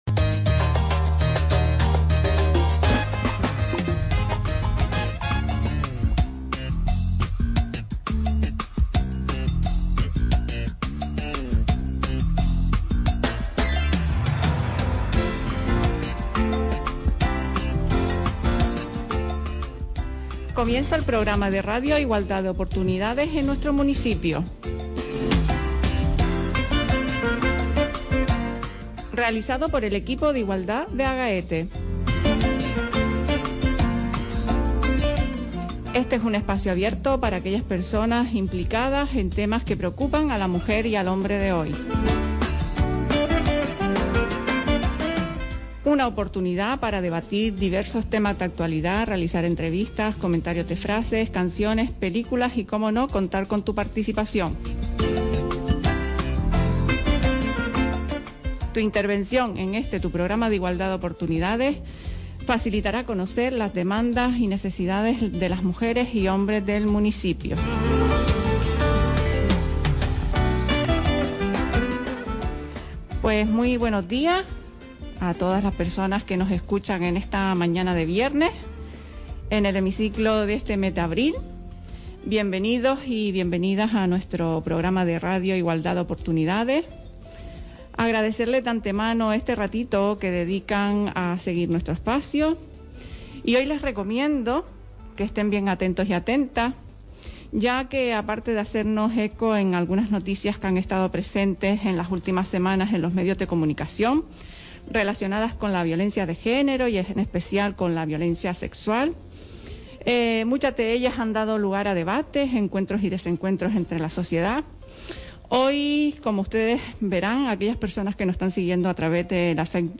Puedes ver la grabación que se realizó de la sesión en directo en el siguiente enlace: